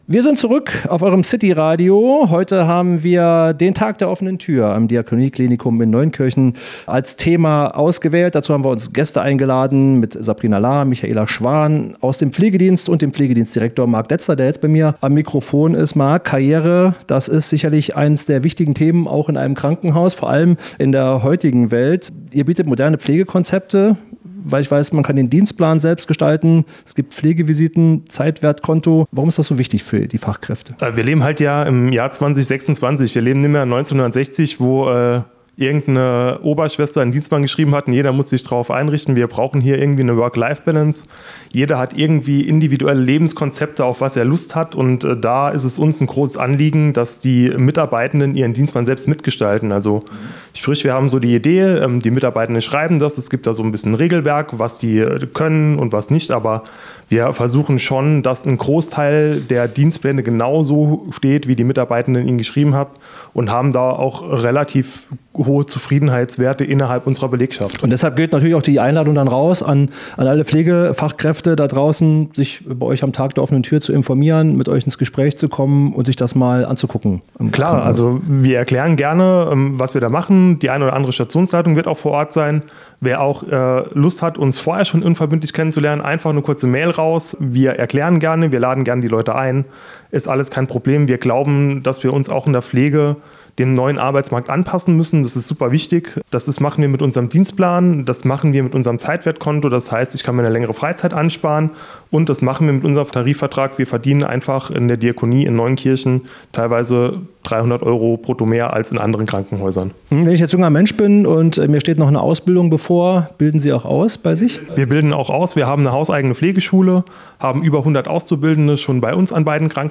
Im Studio von CityRadio Saarland durften wir kürzlich drei spannende Gäste begrüßen: